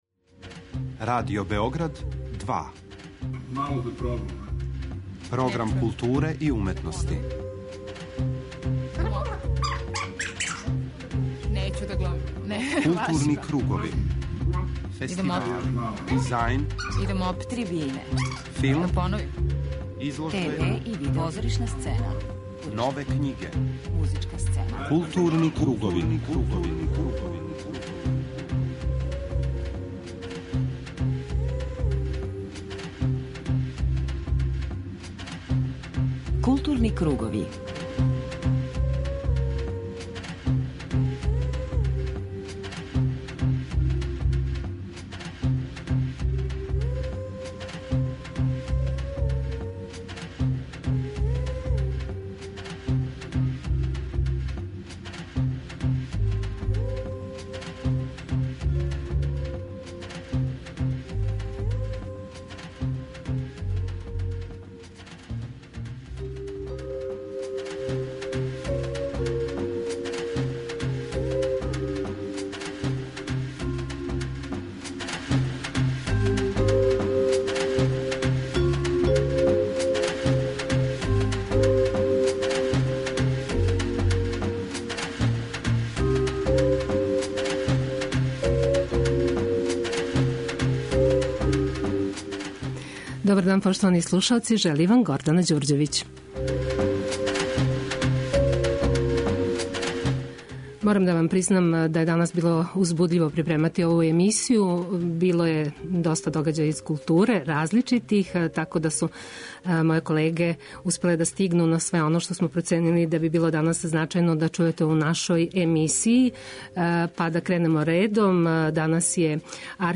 Из садржаја данашњег темата издвајамо гостовање нашег чувеног редитеља Горана Марковића.